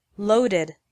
• Listen to the pronunciation